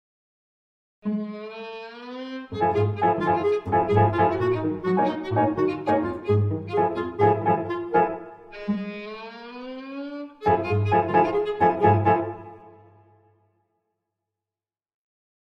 (1999) for clarinet, violin, cello, and piano. 3 minutes.